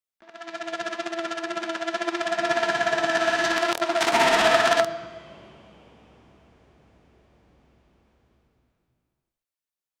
Royalty-free reveal sound effects